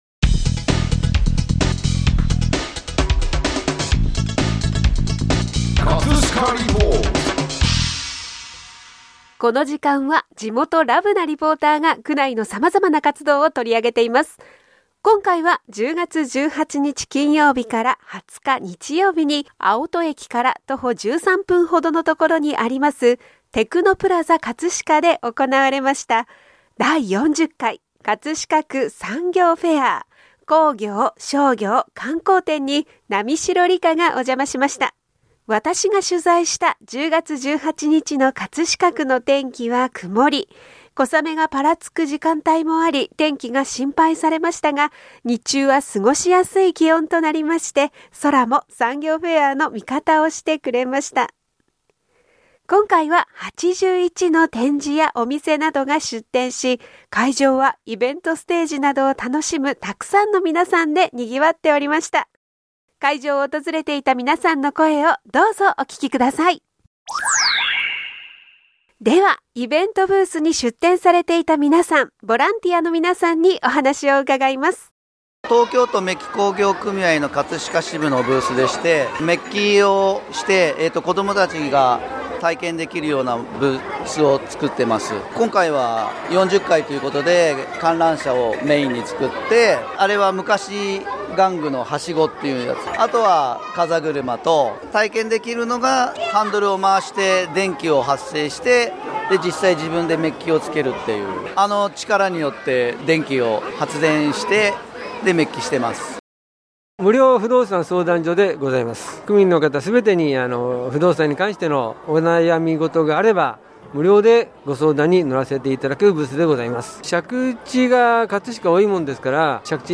今回は、昨年の第39回開催より多い80を超える展示やお店などが出店し、会場はイベントステージなどを楽しむ沢山の皆さんで会場はにぎわっておりました。 会場を訪れていた皆さんの声をどうぞお聴き下さい！